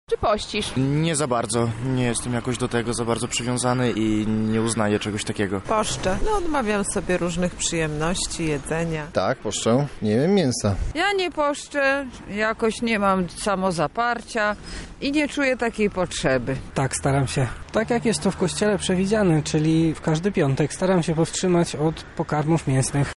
Nasza reporterka zapytała mieszkańców Lublina czy w tym czasie zachowują post.